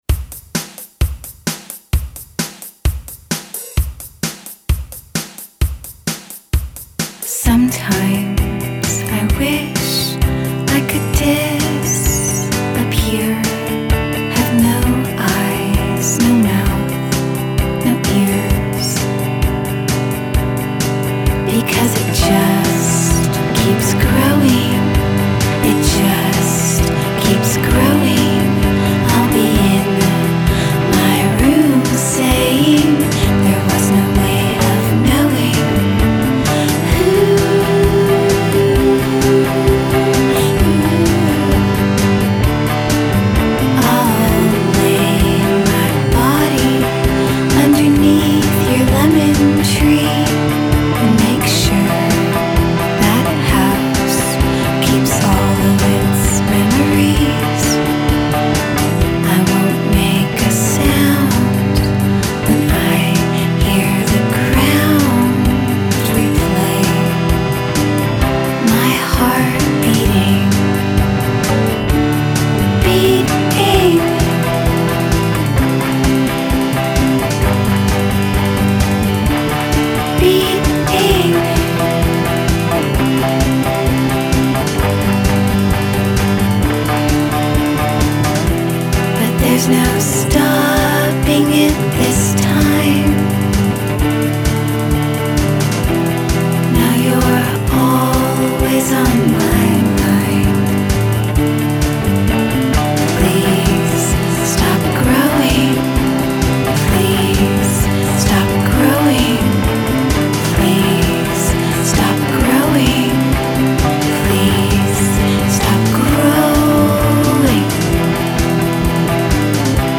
tra abbracci acustici ed elettronica polverosa.